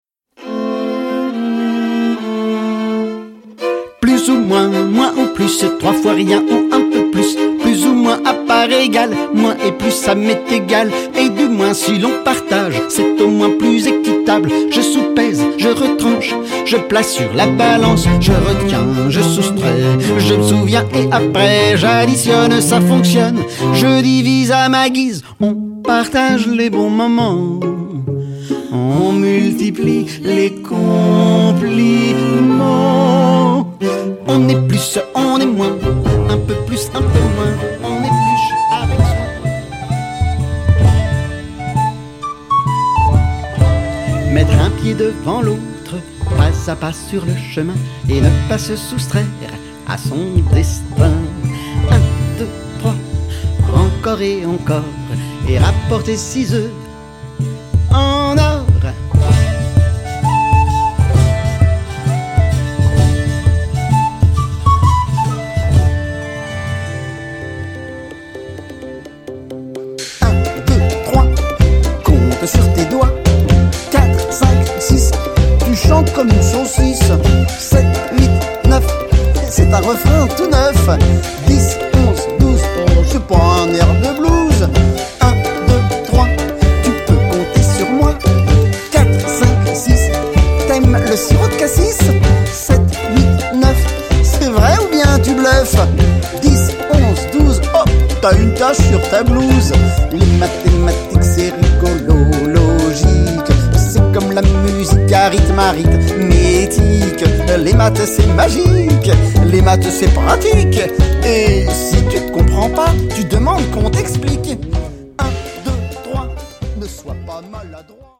* CONTE MUSICAL*